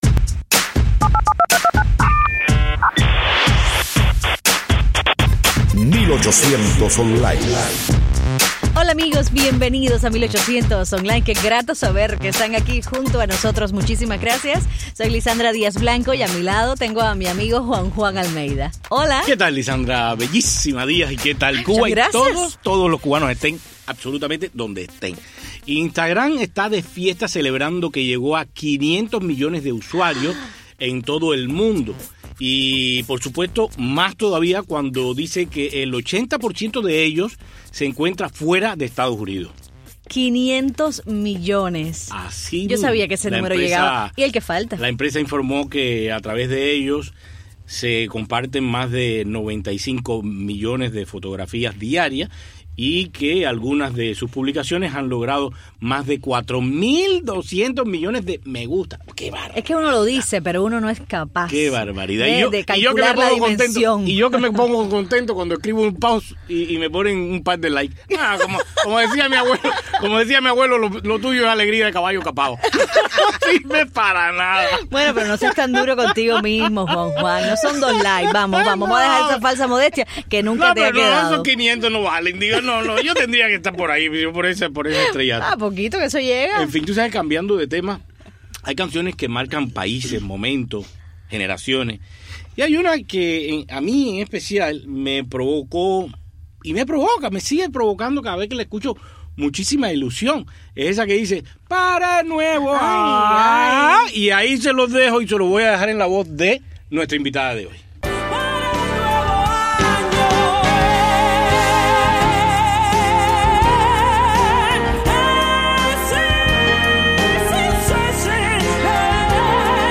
1800 Online con la cantante cubana